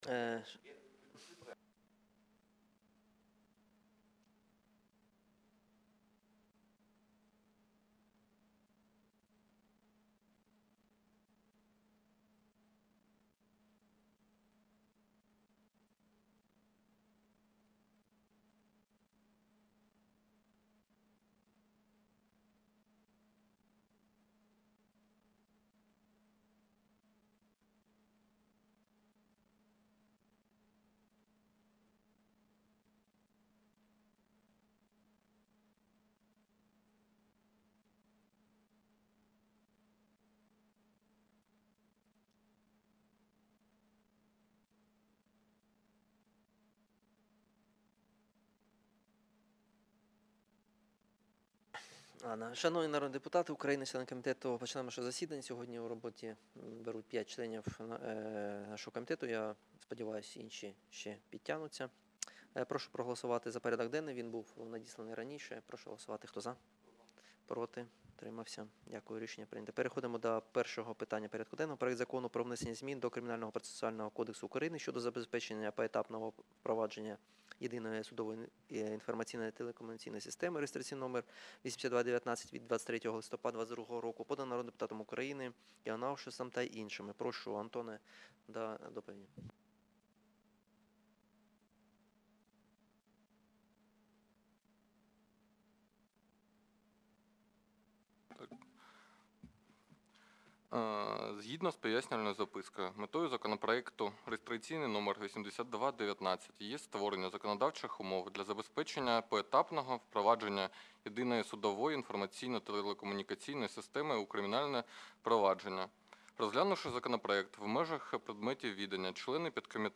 Аудіозапис засідання Комітету від 23.02.2023